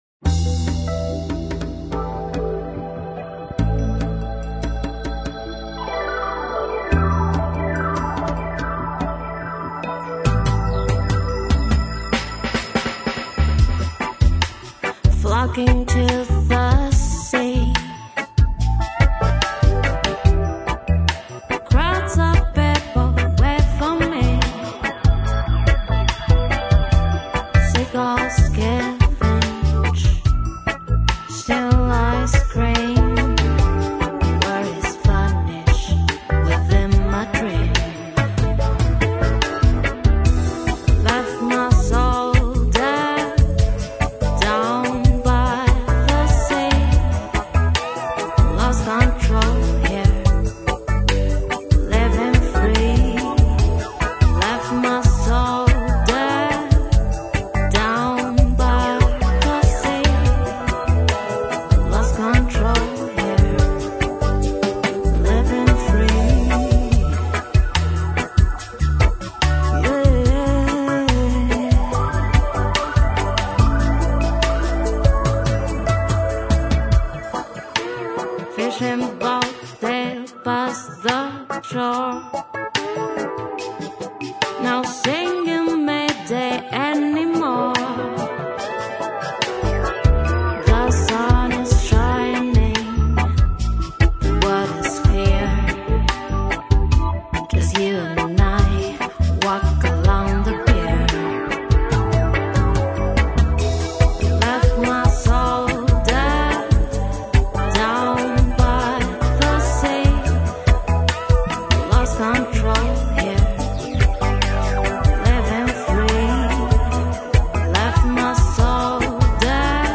Bossa